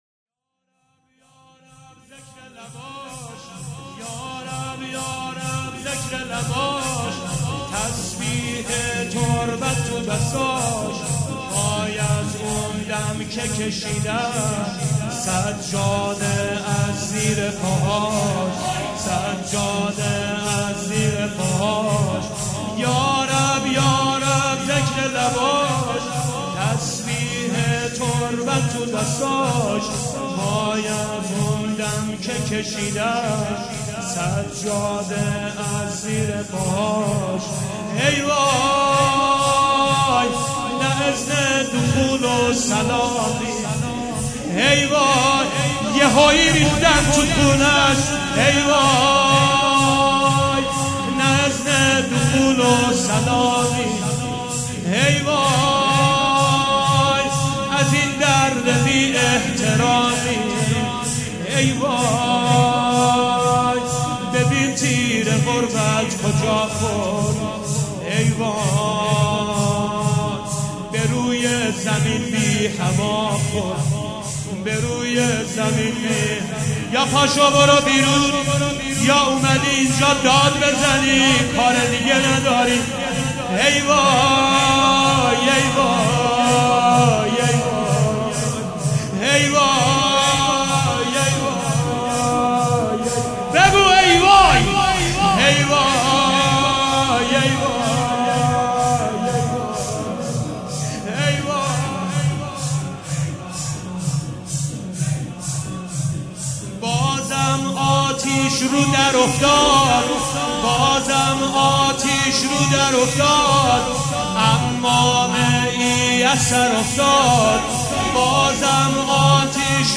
مداحی
02 تیرماه 1398 شهر قزوین
شور روضه ای